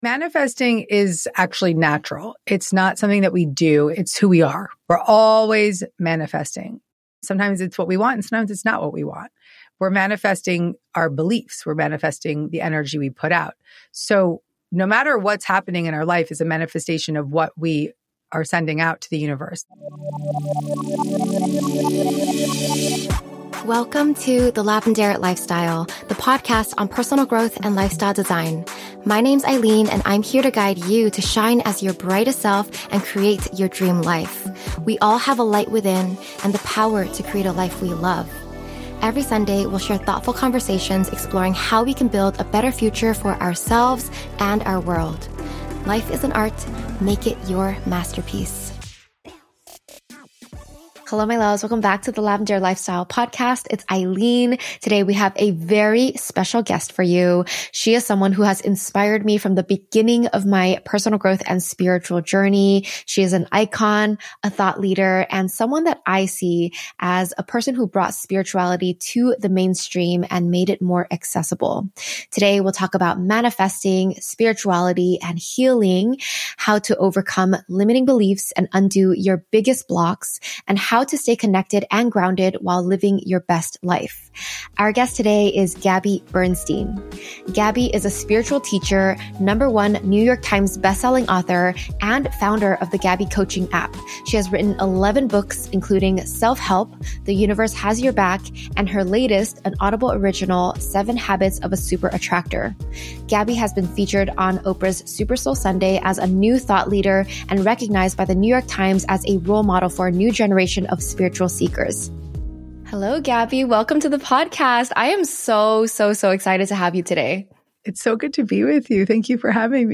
What happens when you stop pressuring yourself and let creative inspiration come naturally? In this conversation, Gabby Bernstein shares how she channeled The Universe Has Your Back during her darkest time, why we're always manifesting based on our beliefs, and how she turned ...